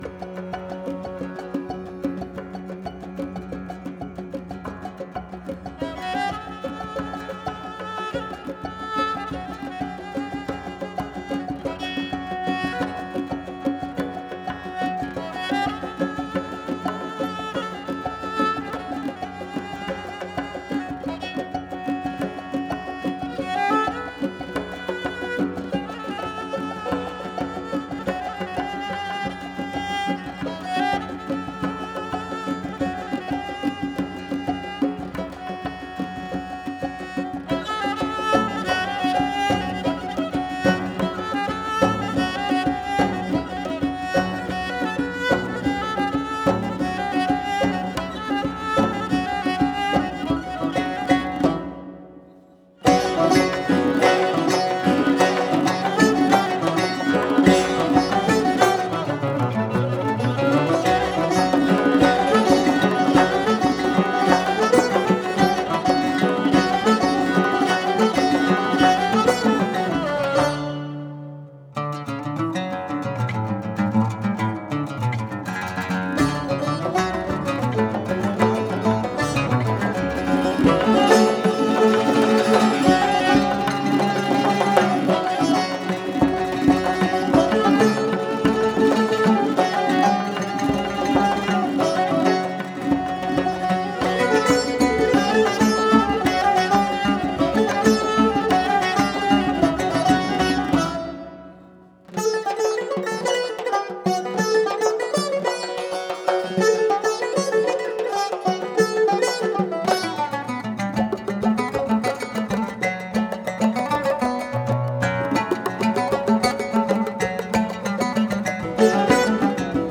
• سنتی ایرانی
دسته : سنتی ایرانی